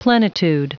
Prononciation du mot plenitude en anglais (fichier audio)
Prononciation du mot : plenitude